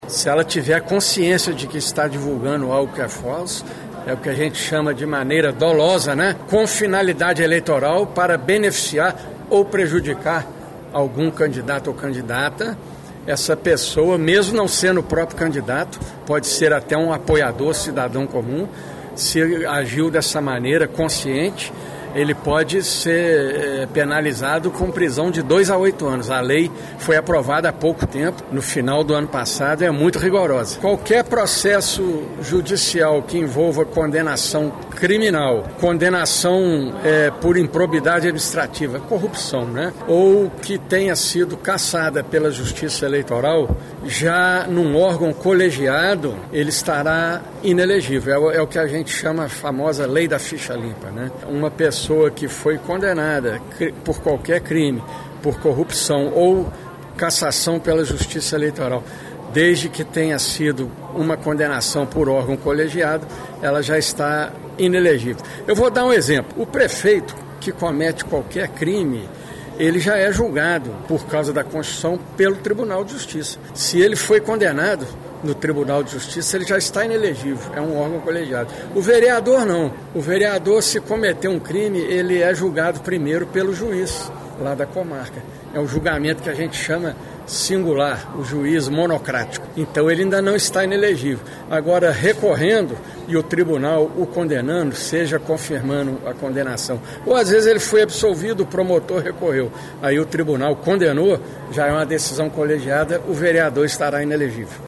As falhas do microfone sem fio durante as apresentações, não chegou a atrapalhar, mas incomodou as mais de 200 pessoas no plenário da Câmara, como também o “ronco berrante” de um dos ar – condicionados.